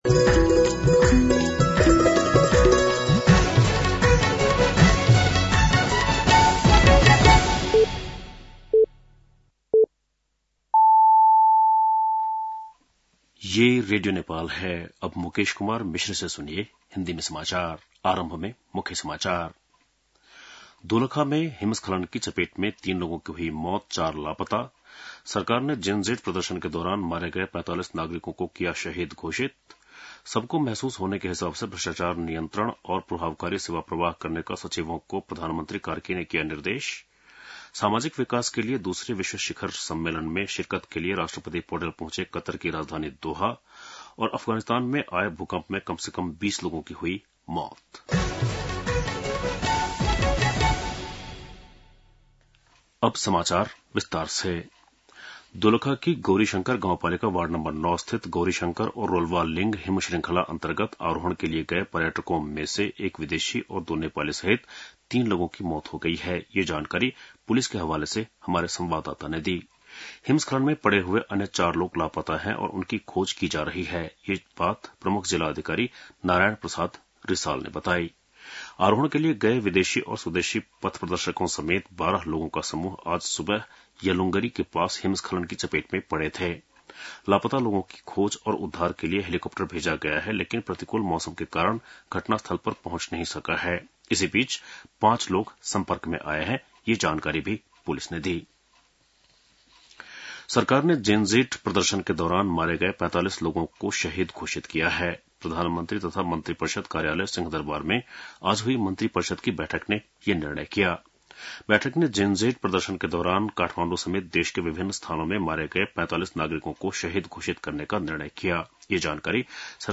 बेलुकी १० बजेको हिन्दी समाचार : १७ कार्तिक , २०८२